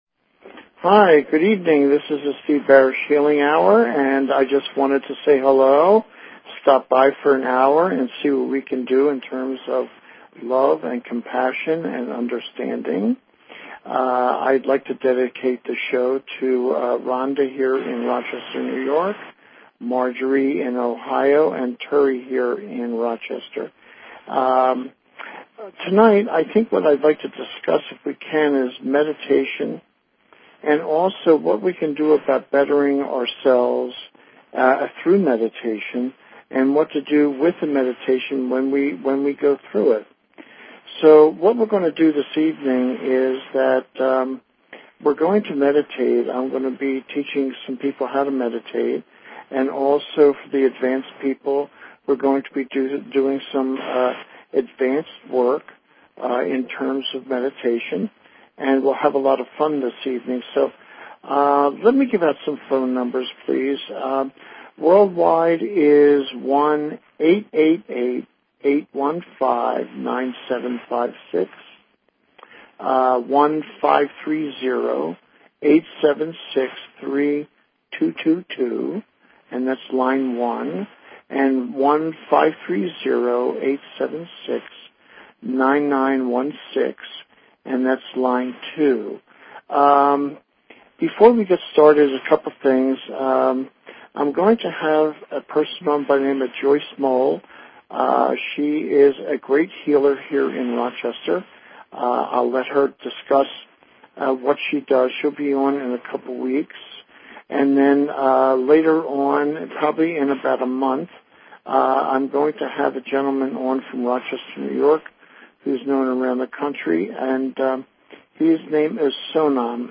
Talk Show Episode, Audio Podcast, The_Healing_Hour and Courtesy of BBS Radio on , show guests , about , categorized as